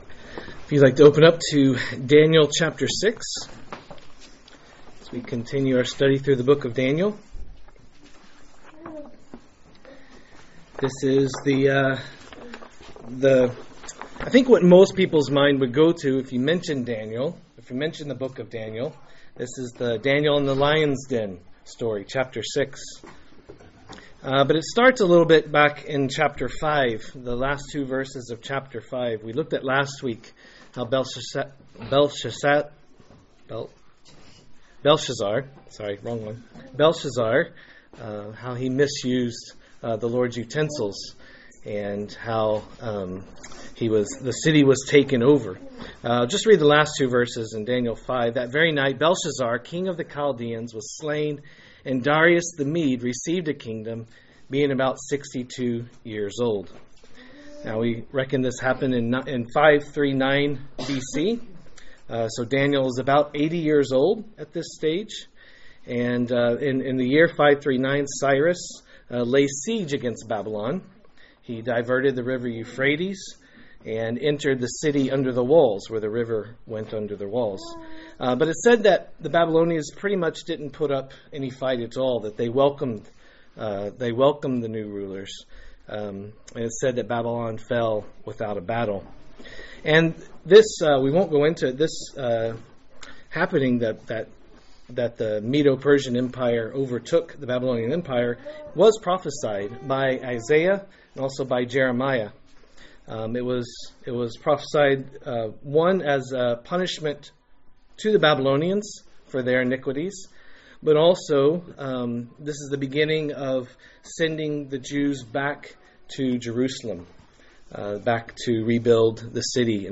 A message from the series "Daniel." Daniel 6